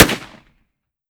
30-30 Lever Action Rifle - Gunshot A 002.wav